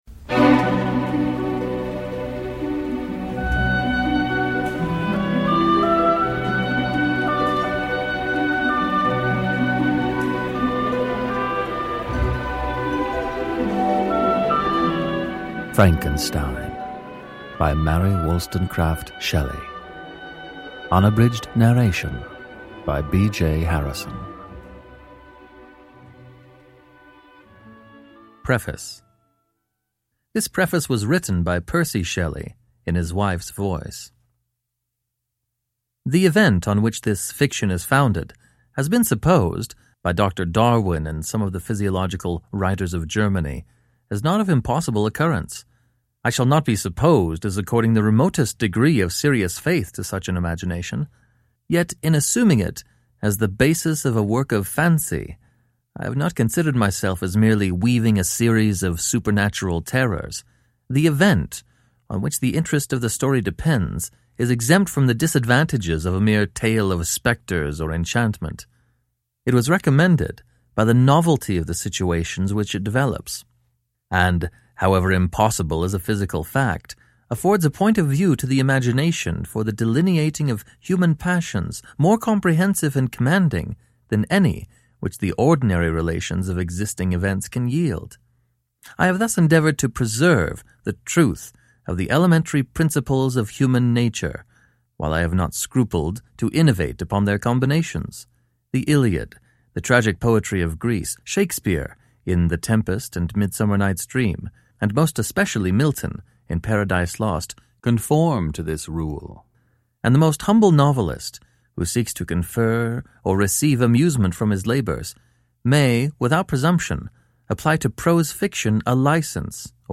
Frankenstein and Other Stories – Ljudbok